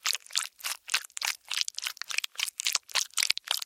Звуки слайма
Перекидываем слайм из руки в руку